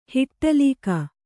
♪ hiṭṭalīka